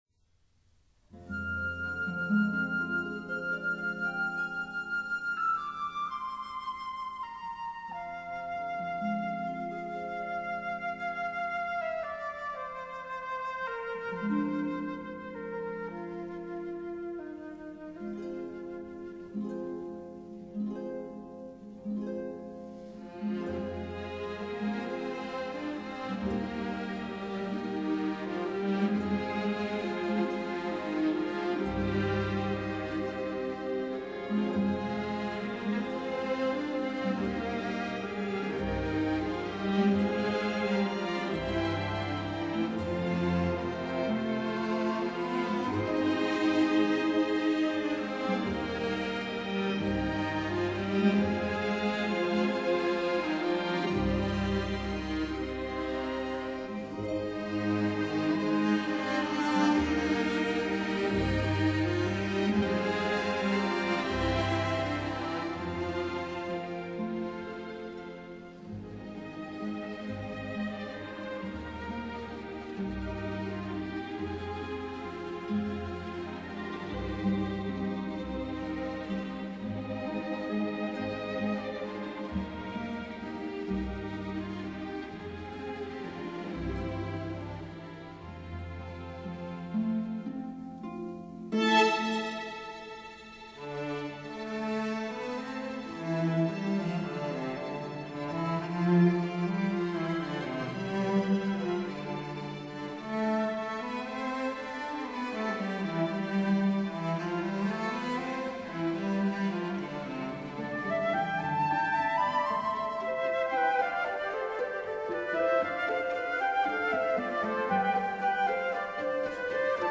violin & conductor